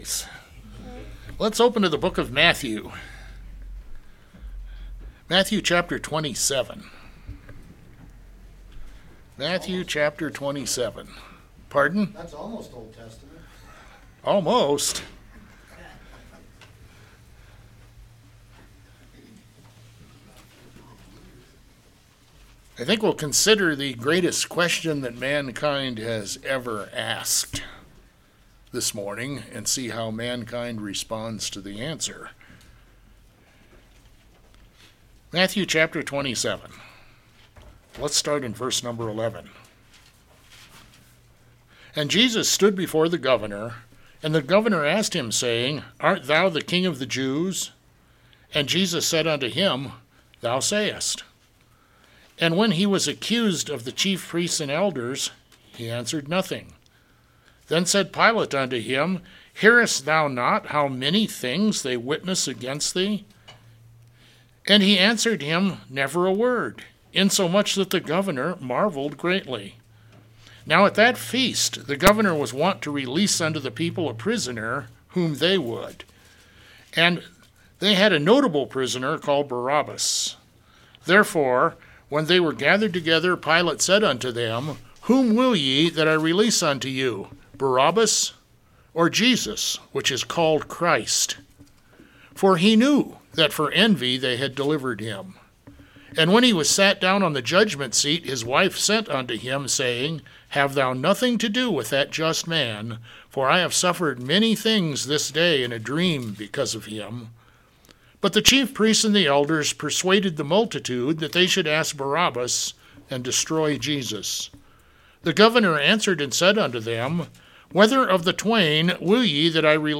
Preacher: Black Forest Bible Church